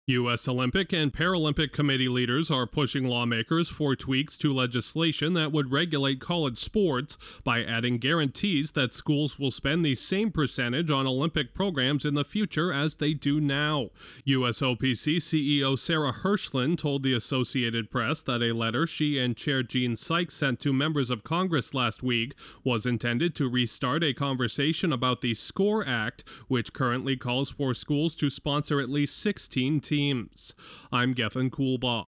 U.S. Olympic leaders are urging Congress to set minimum spending limits for Olympic programs. Correspondent